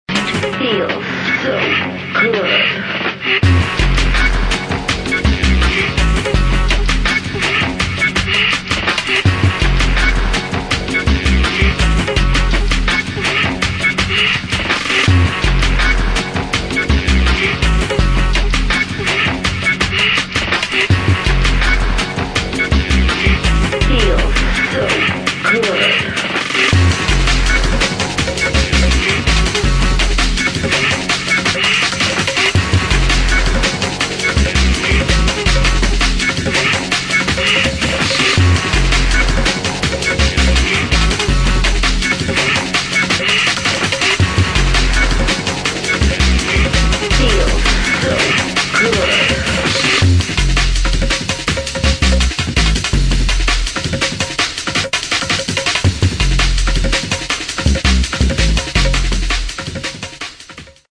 [ DRUM'N'BASS / JUNGLE / OLDSKOOL ]